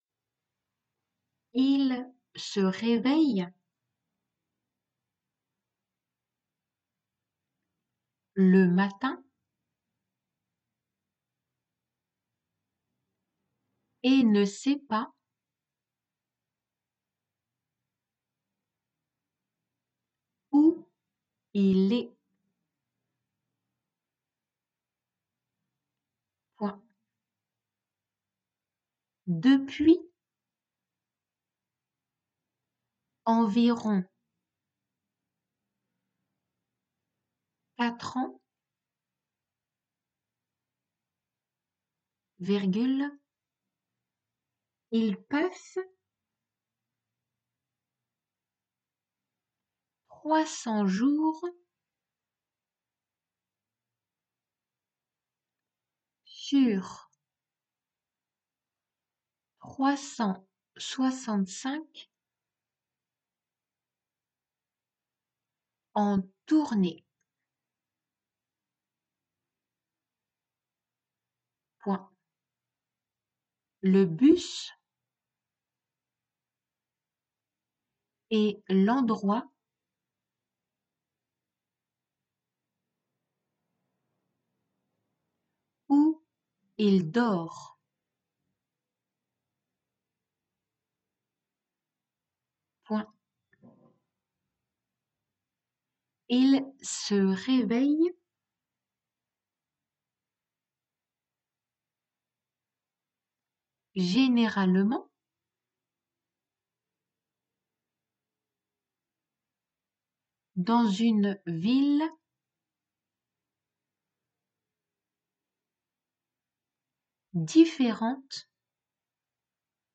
デイクテ
デイクテの速さで